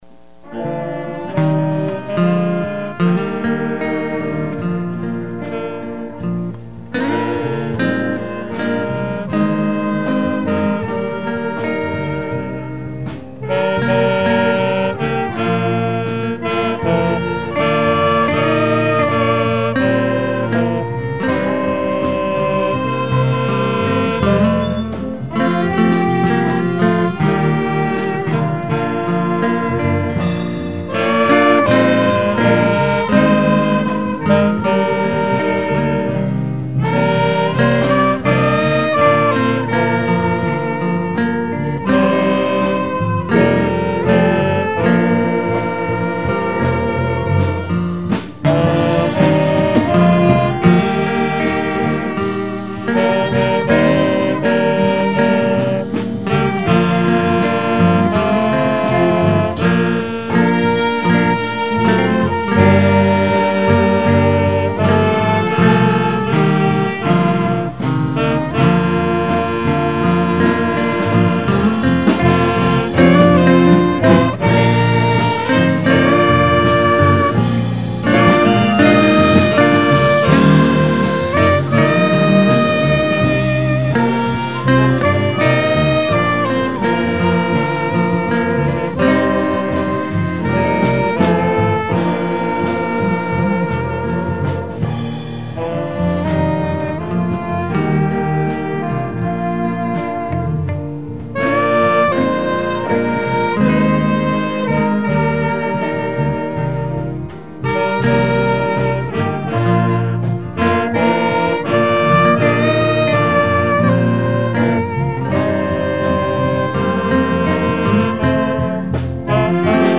02/21/10 Sunday Services